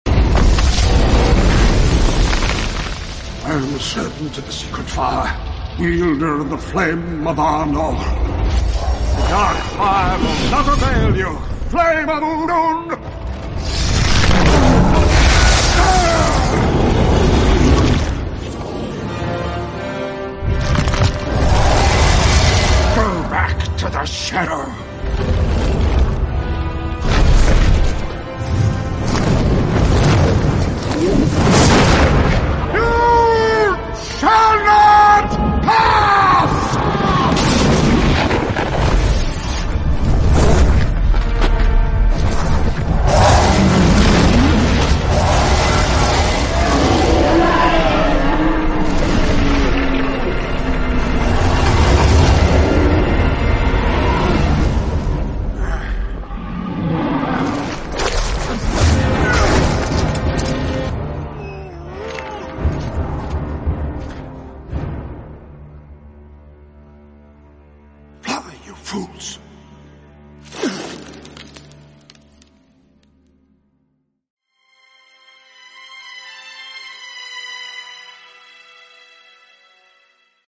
New dizayn neopixel proffie lightsaber sound effects free download
New dizayn neopixel proffie lightsaber the balrog soundeffect and fire effect